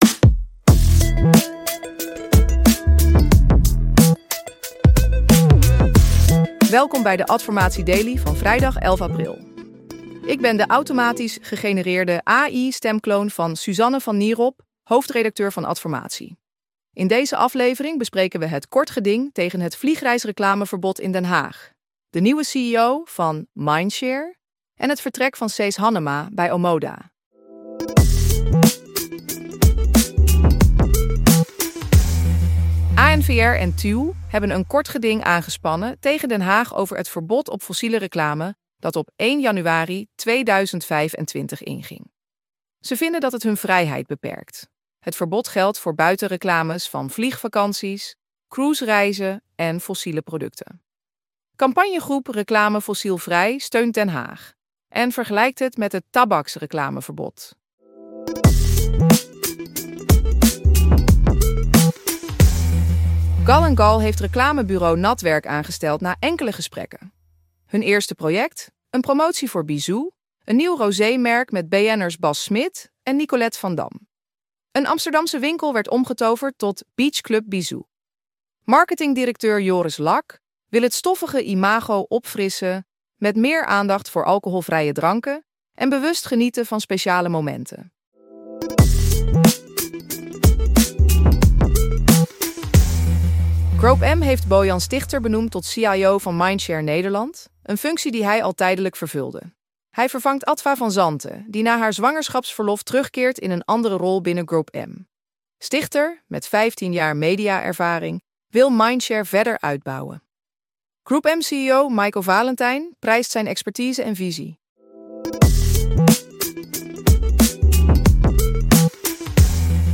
Geïnteresseerd in een automatisch gegenereerde podcast voor jouw content?